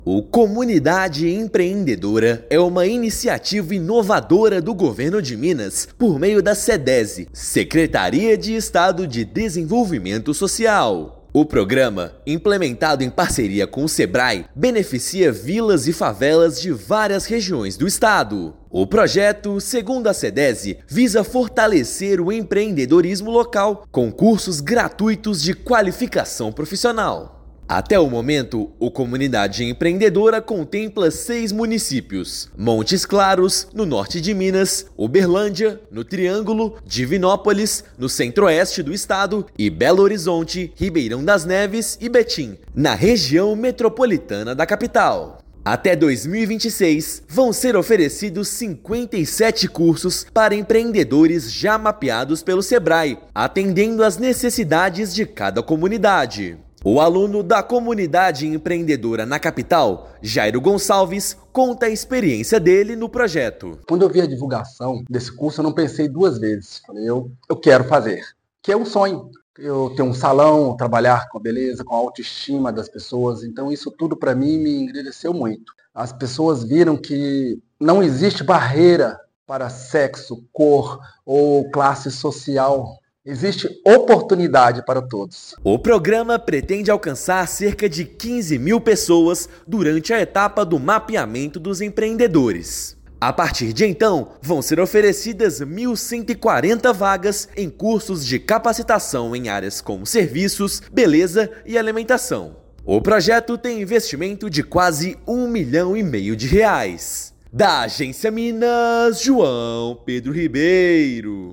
Moradores de 20 vilas e favelas mineiras são atendidos por programa de capacitação profissional da Sedese. Ouça matéria de rádio.